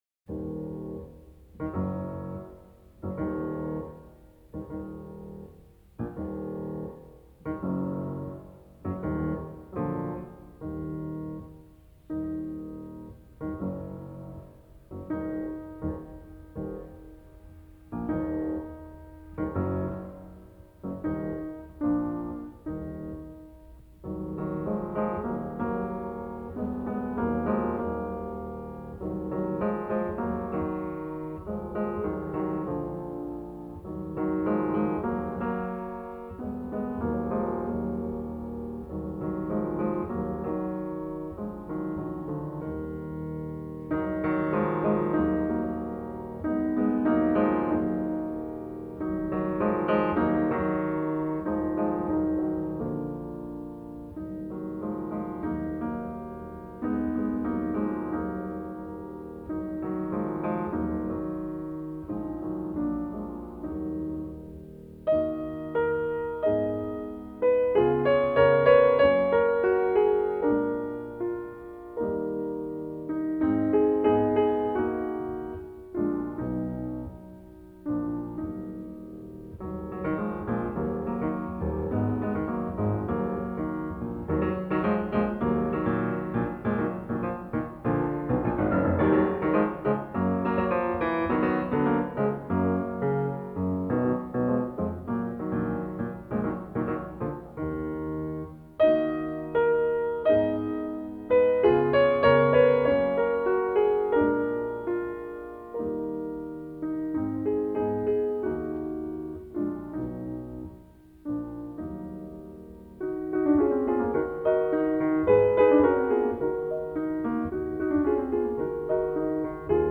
موسیقی کلاسیک زیبا به نام " سوغاتی از پورتو ریکو " از آهنگساز آمریکایی " لوییس مورو گاتچاک "